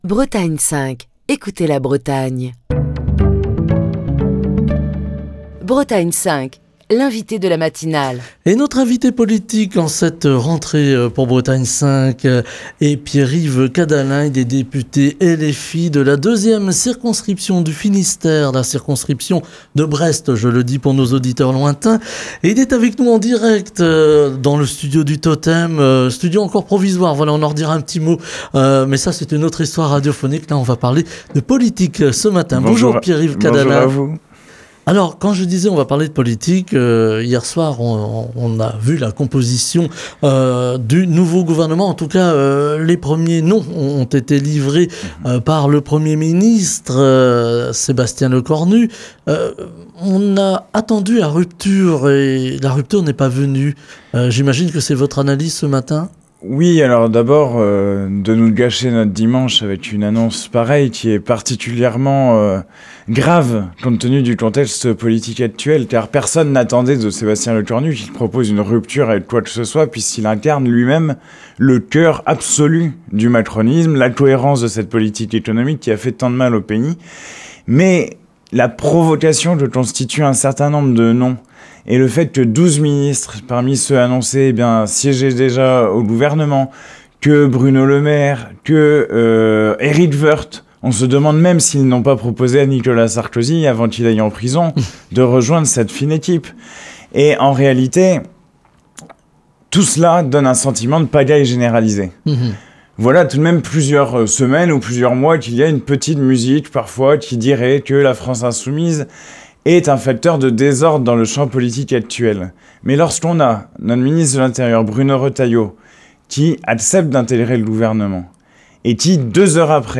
Pierre-Yves Cadalen, député LFI de la deuxième circonscription du Finistère (Brest), était l'invité politique de la matinale de Bretagne 5 ce lundi.